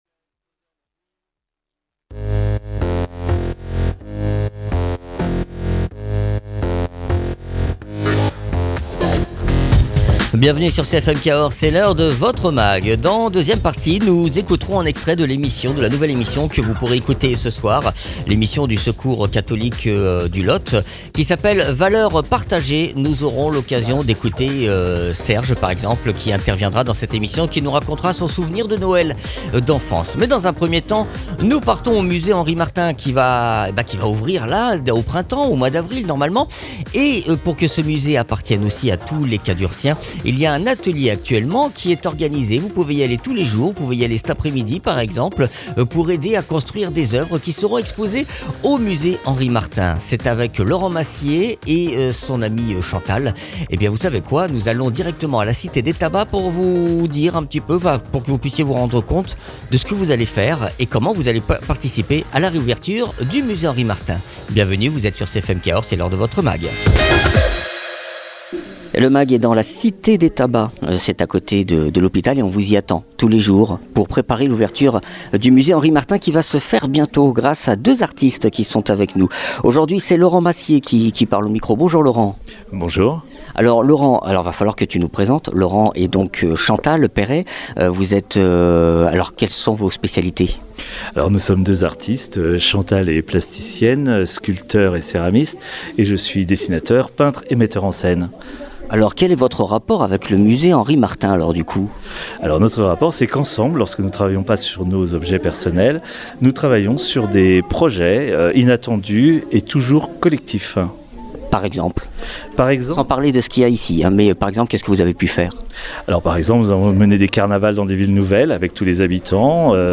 Les cadurciens sont invités à s’approprier le nouveau musée Henri Martin, qui réouvre au printemps, dans des ateliers animées apr des artistes. Egalement dans ce mag, un extrait de la nouvelle émission du secours catholique du Lot ’Valeurs partagées’.
Mags